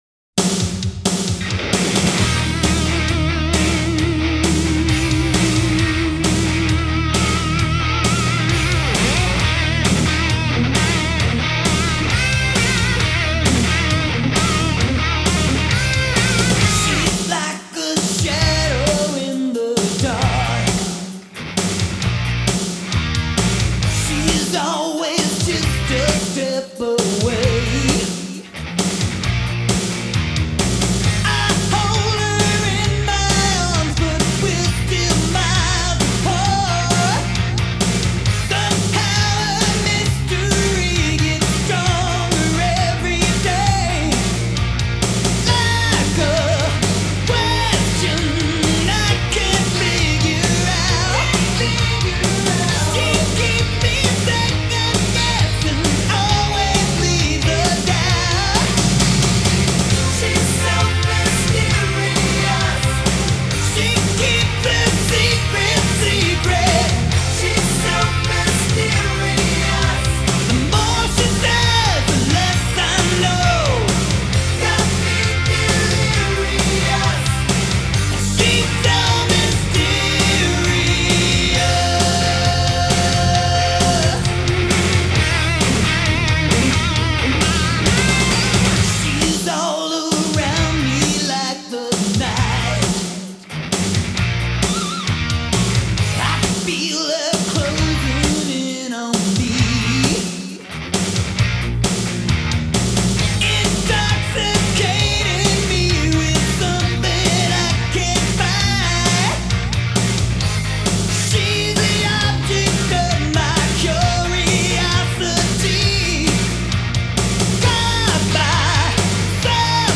Назад в Rock